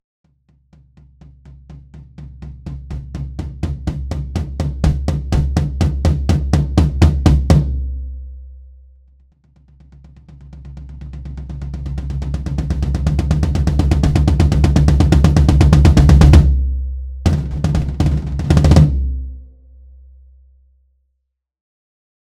Честная библиотека барабанной установки с записью на 12 микрофонов. Есть аналоги?
еще одна попытка справилться с флор-томом без перезаписи:
Мне он тоже кажется резиновым мячиком без собственных резонансов вообще.